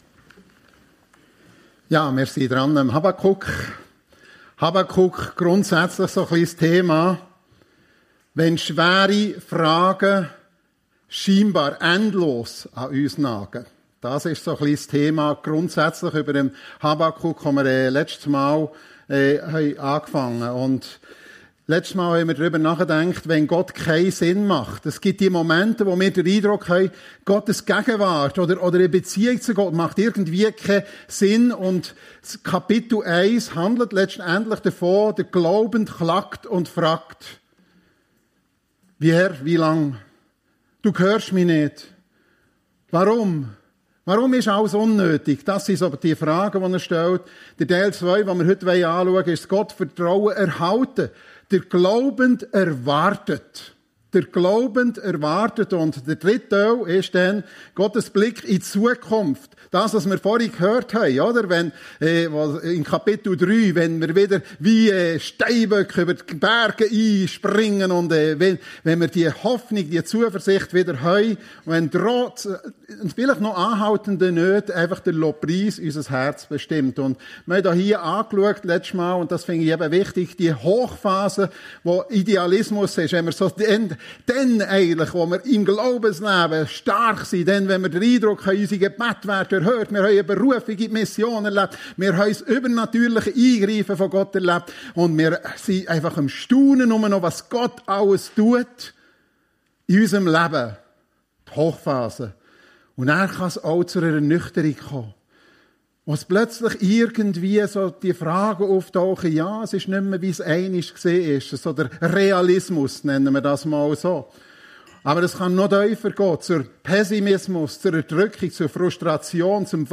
Wie kann ich glauben? - Habakuk 2 ~ FEG Sumiswald - Predigten Podcast